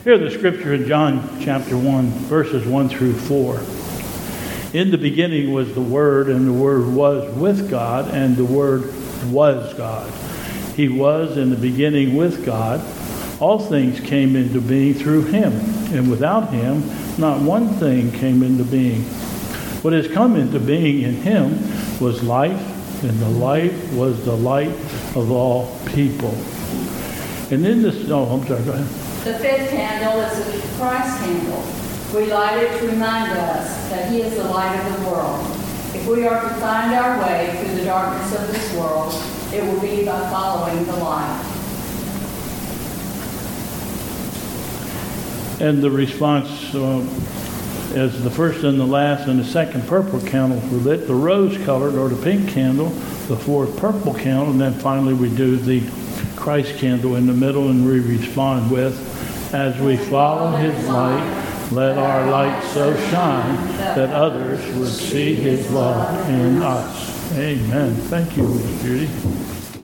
Processional